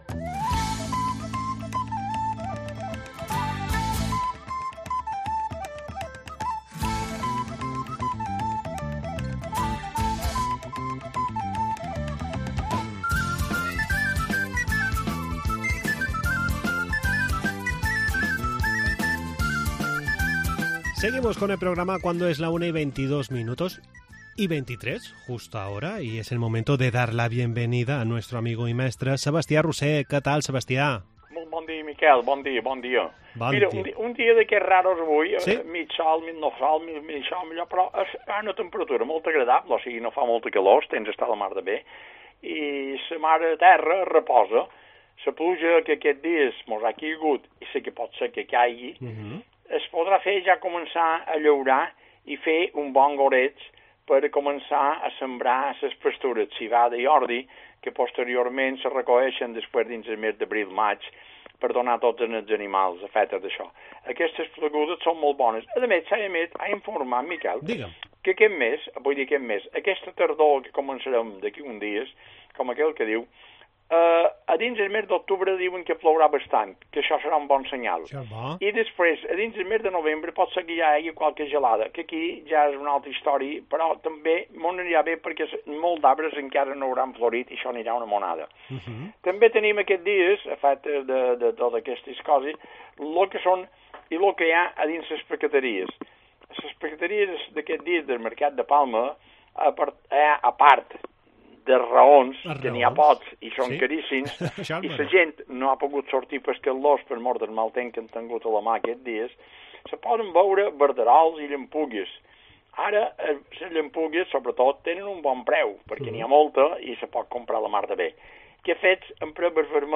Redacción digital Madrid - Publicado el 21 sep 2023, 15:22 - Actualizado 21 sep 2023, 15:22 1 min lectura Descargar Facebook Twitter Whatsapp Telegram Enviar por email Copiar enlace Espacio semanal donde repasamos las costumbres mallorquinas y os contamos las ferias y fiestas de los próximos días. Entrevista en 'La Mañana en COPE Más Mallorca', jueves 21 de septiembre de 2023